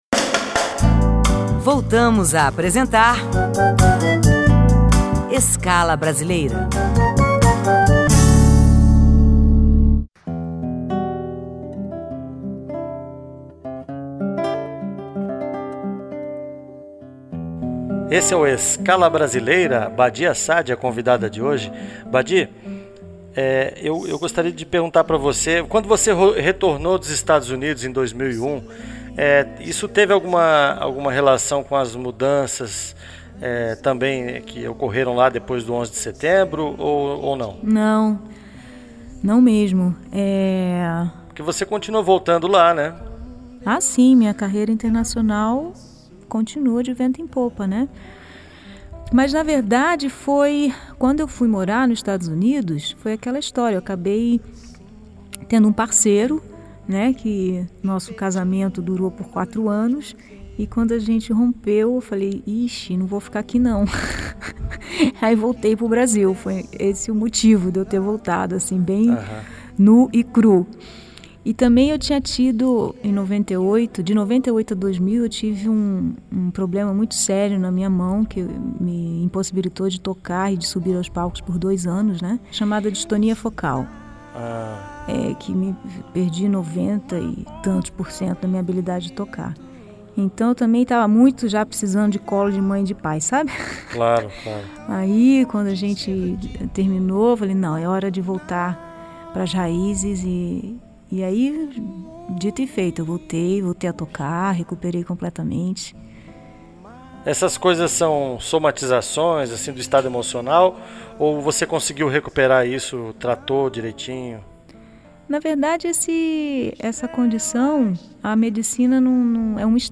Música e entrevistas com artistas brasileiros